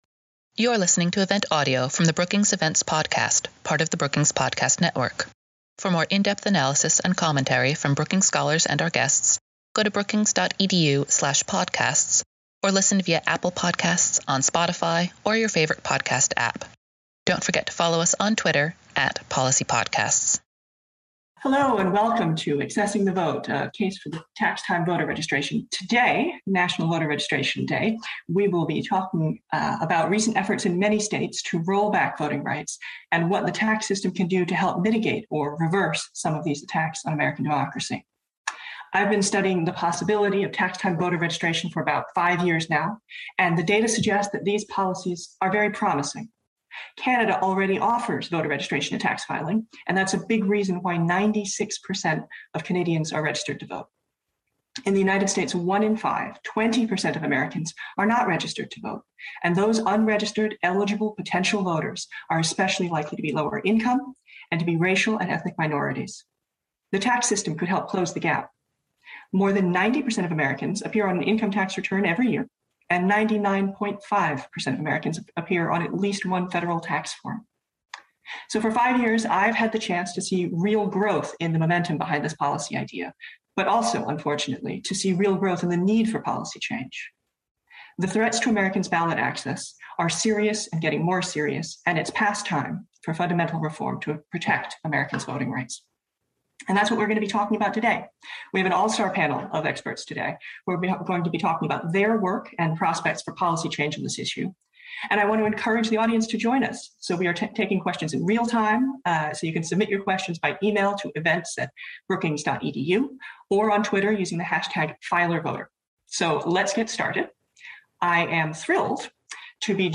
Viewers also heard from Rep. Bonnie Watson Coleman (D-N.J.), who has introduced legislation allowing voters to register when filing their taxes.
Fireside chat
Panel discussion